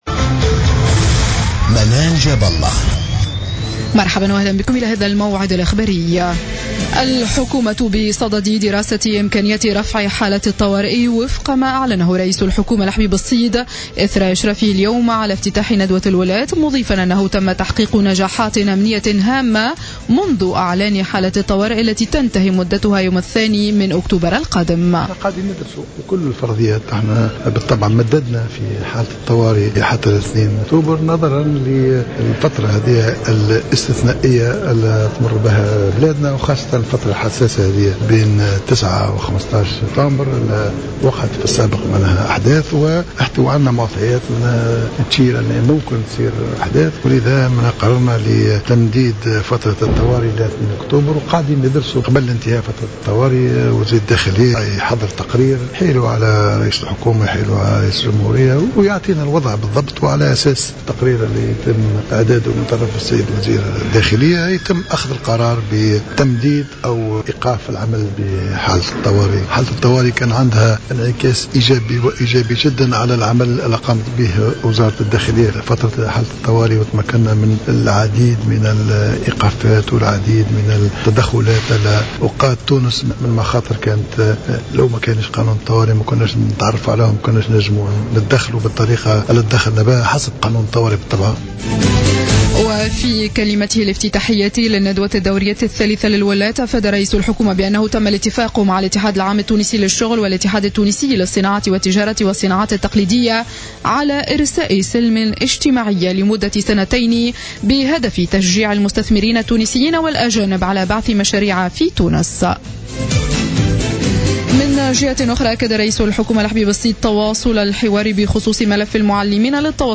نشرة أخبار السابعة مساء ليوم الأحد 13 سبتمبر 2015